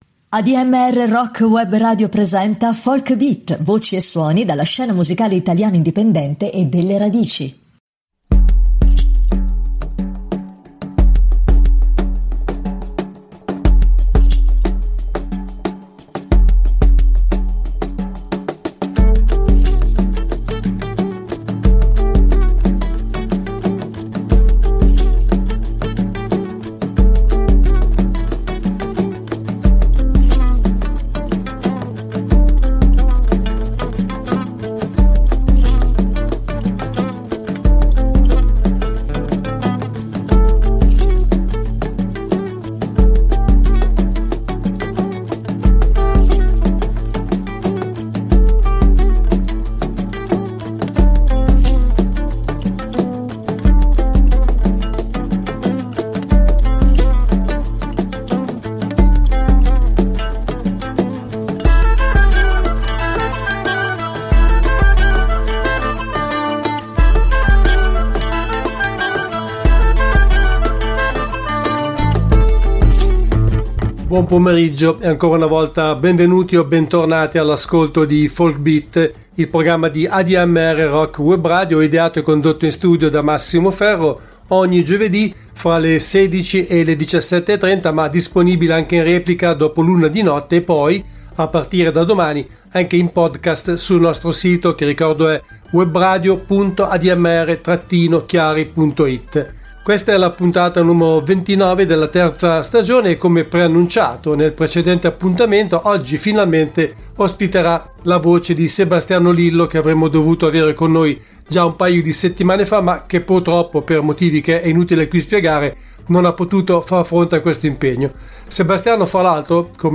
Ospite del programma al telefono